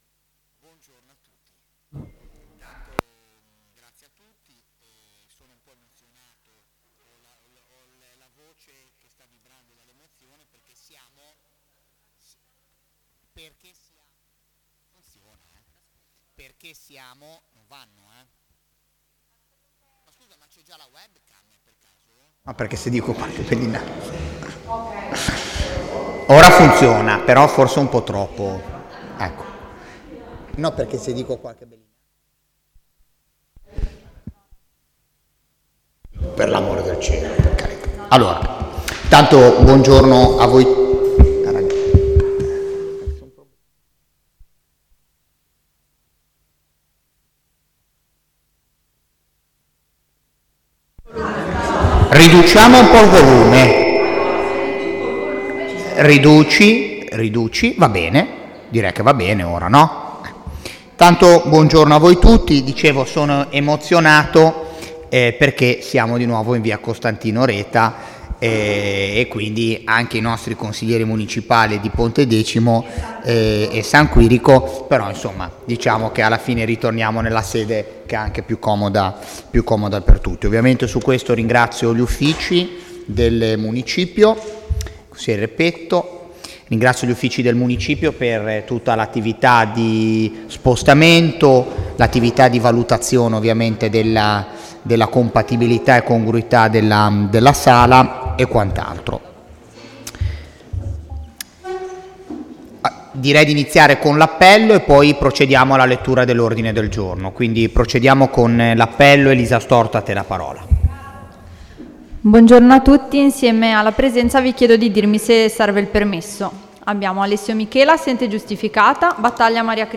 Convocazione Consiglio
seduta_consiglio_5_marzo_25.mp3